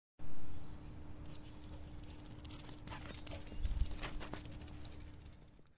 Tiếng bước chân Chó chạy ngang qua, nhanh kèm hơi thở
Thể loại: Tiếng vật nuôi
Description: Tải về hiệu ứng âm thanh dog running sound effect, tiếng bước chân của chú chó chạy lướt qua, rượt đuổi với tốc độ nhanh chóng, kèm theo hơi thở hổn hển mạnh mẽ, mang lại cảm giác chân thực cho video của bạn.
tieng-buoc-chan-cho-chay-ngang-qua-nhanh-kem-hoi-tho-www_tiengdong_com.mp3